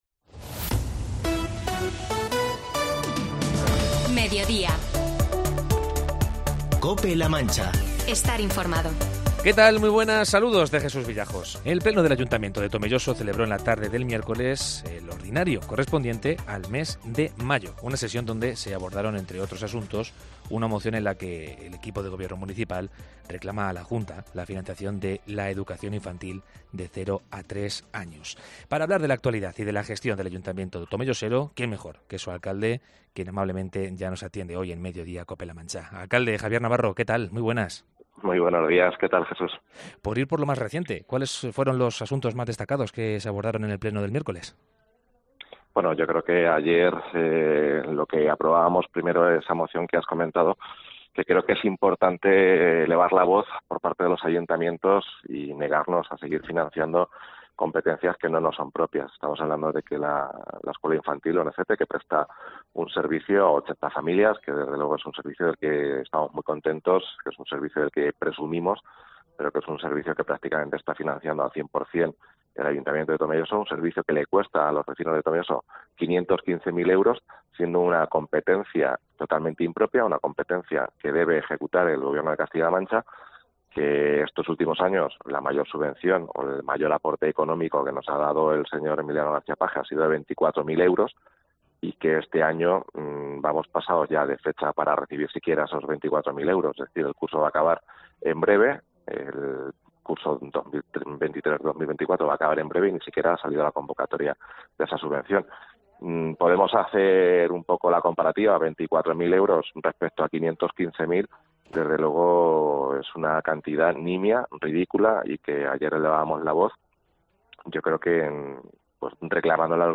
Entrevista con el alcalde de Tomelloso, Javier Navarro, sobre los asuntos de la actualidad local
Medidas adoptadas en el último pleno, gestión, infraestructuras, políticas sociales, cultura... Hoy, en Mediodía Cope La Mancha hablamos de todos los asuntos de actualidad con el alcalde de Tomelloso, Javier Navarro.